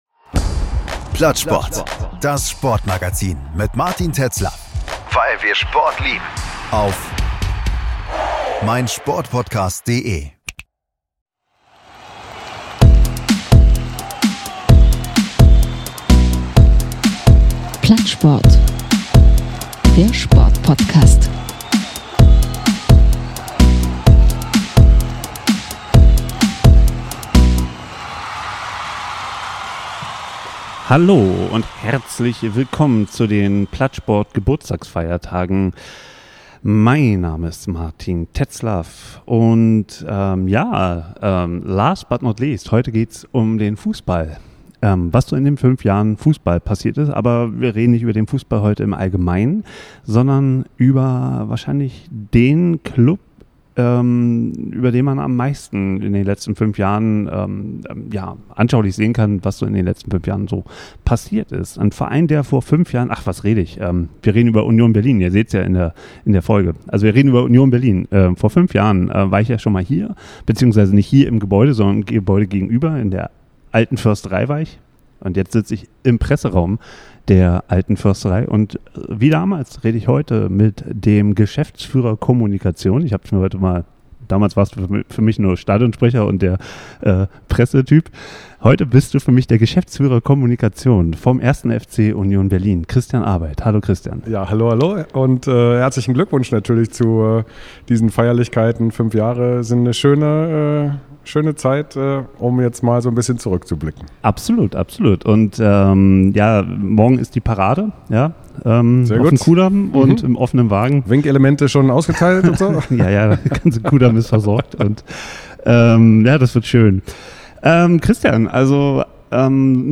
Vor fünf Jahren führten wir unser erstes Interview.